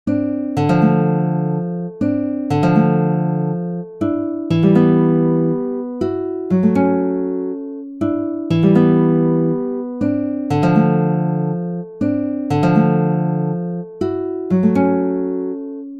俏皮的原声吉他弹奏
描述：4个无缝小节的原声吉他，敲打着一些缓慢的、怀旧的和弦。 高度摇摆，有一种三段式的感觉。
Tag: 67 bpm Pop Loops Guitar Acoustic Loops 2.41 MB wav Key : D